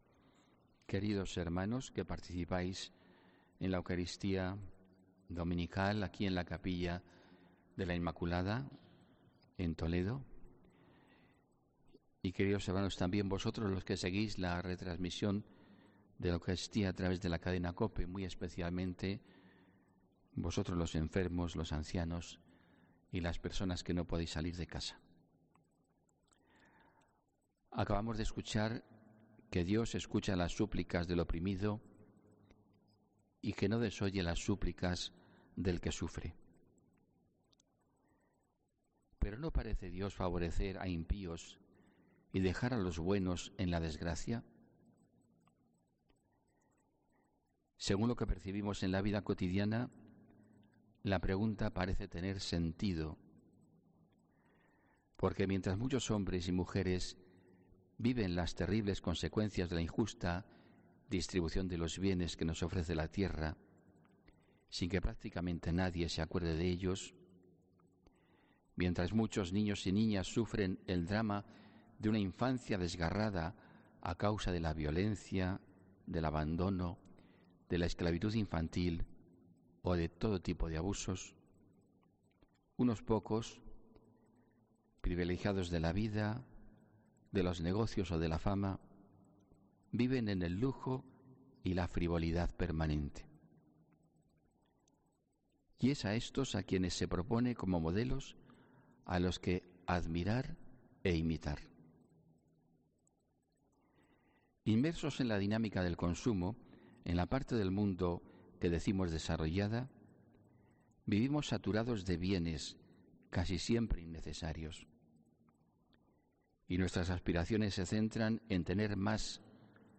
HOMILÍA 27 OCTUBRE